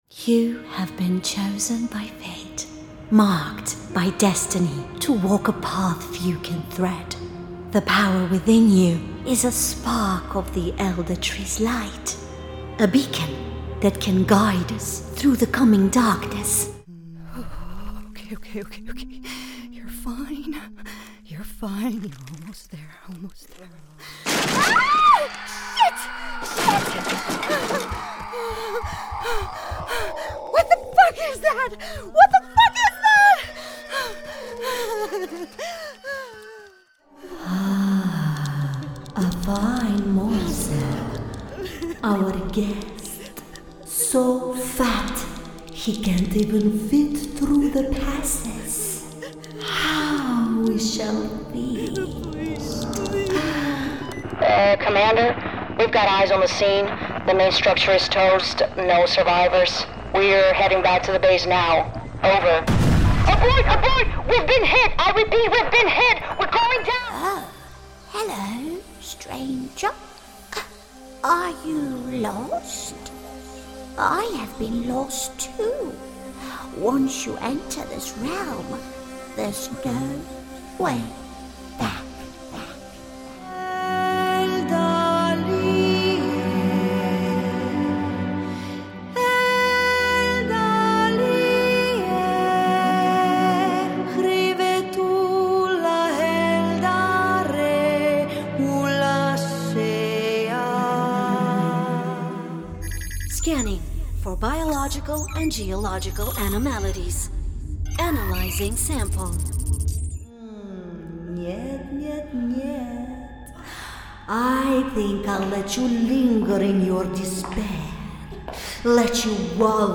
Videogames
Cabine tratada
Microfone Rode NT1a
Mezzo-soprano